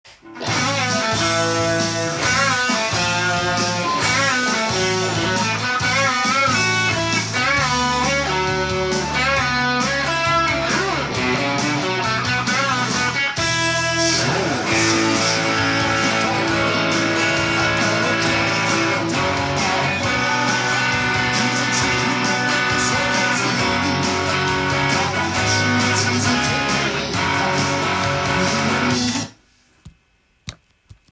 エレキギターTAB譜
音源に合わせて譜面通り弾いてみました
チョーキングしながら他の弦を巻き込んでピッキングする
奏法が使われています。
リズムはポップス定番の８ビート系になるので弾きやすくリズムが